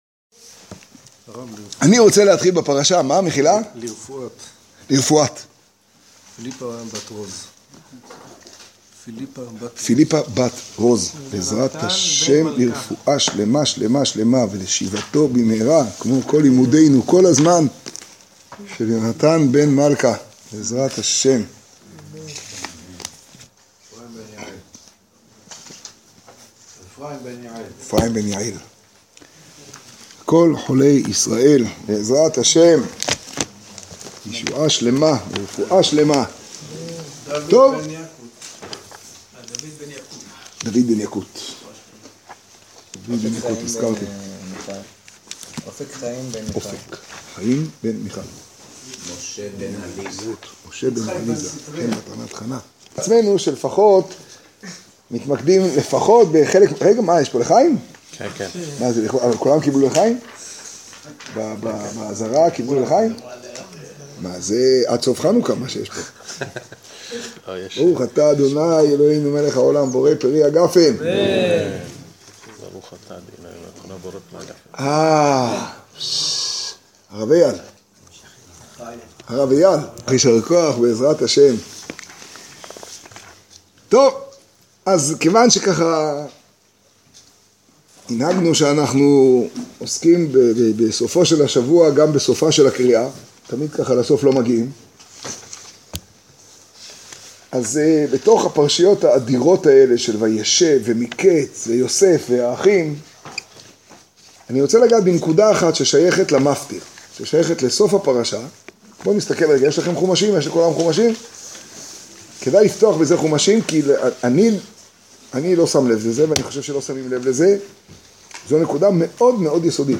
השיעור במגדל, פרשת מקץ תשעה.